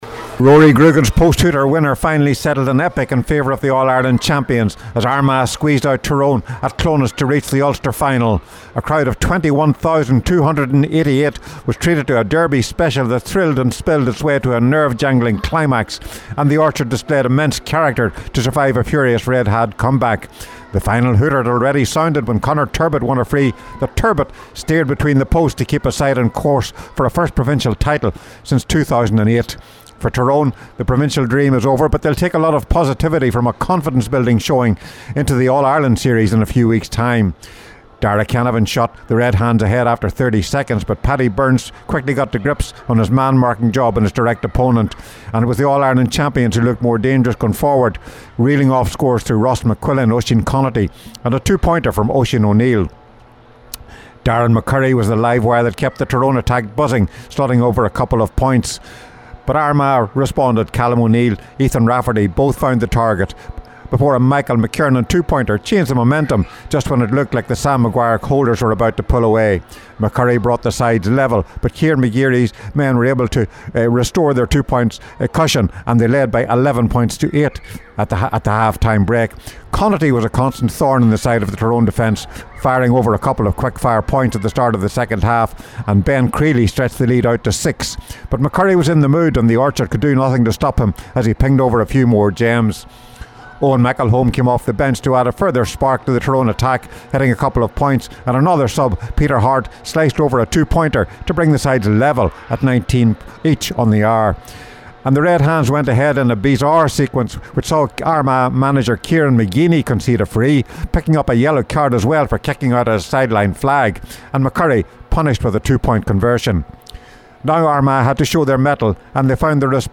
With the full time report for Highland Radio Sport